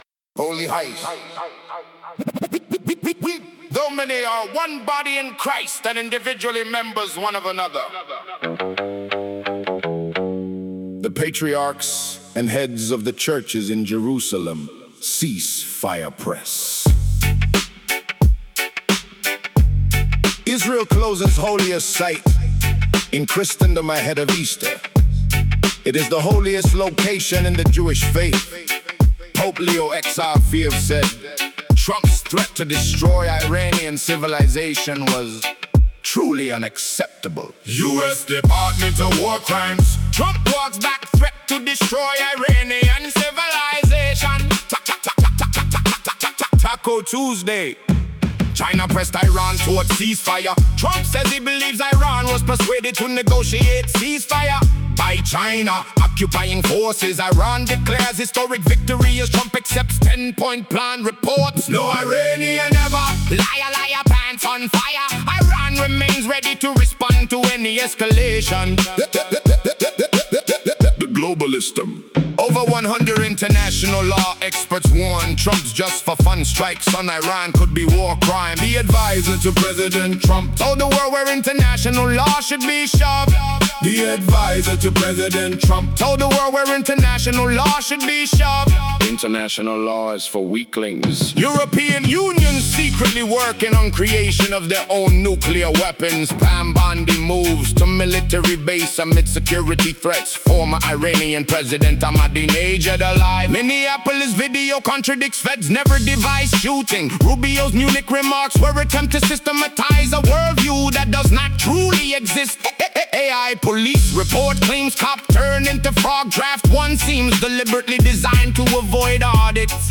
toe tap'n
🎵 TagTheme Song